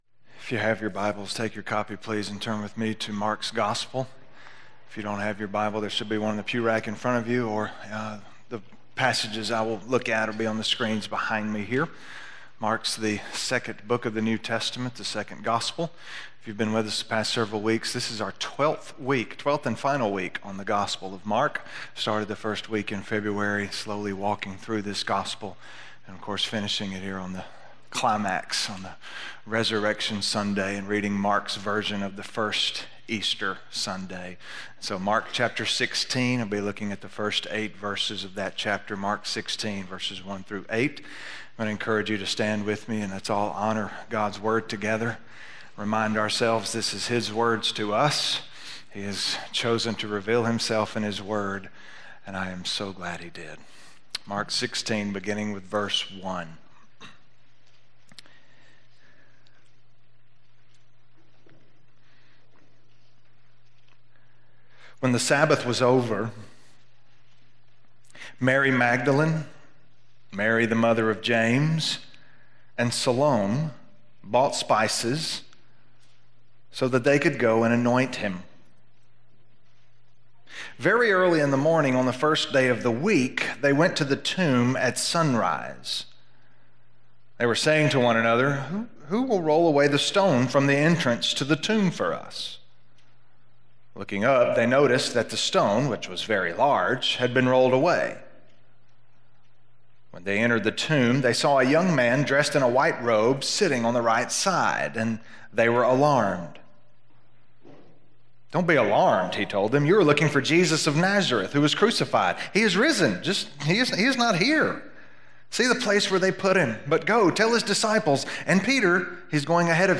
Resurrection - Sermon - West Franklin